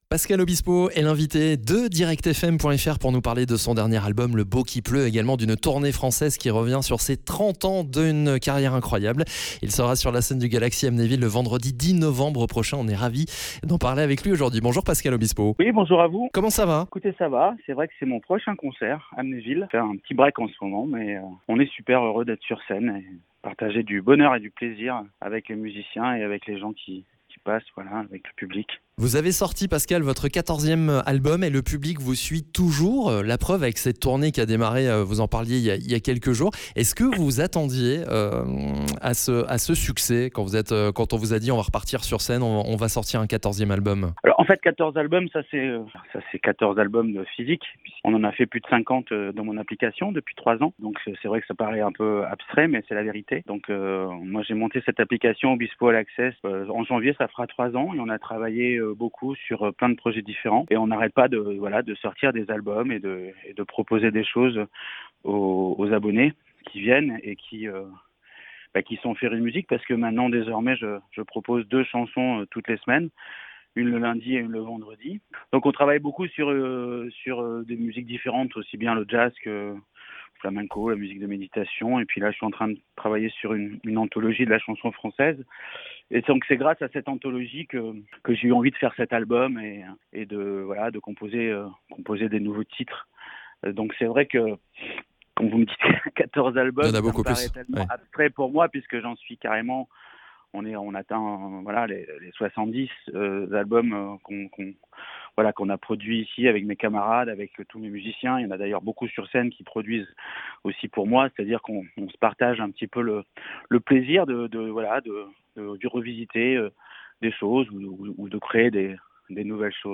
L'interview de Pascal Obispo